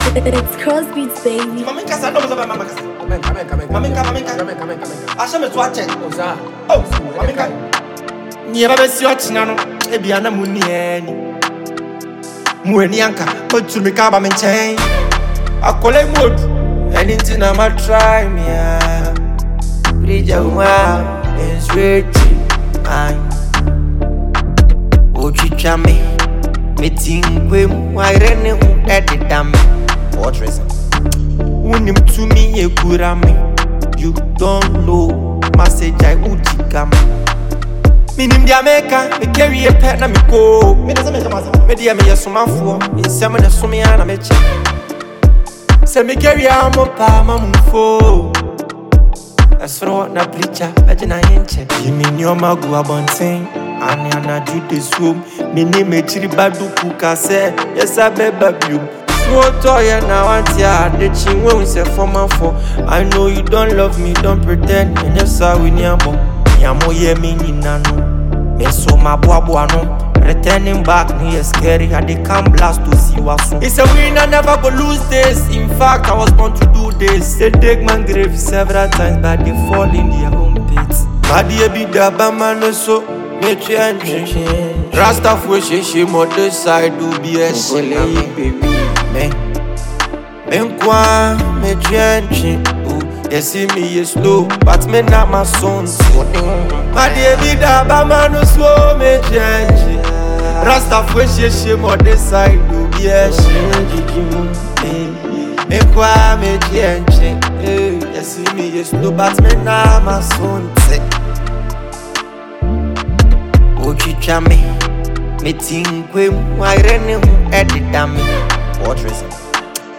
a bold and emotionally charged track
rides on a dark, intense beat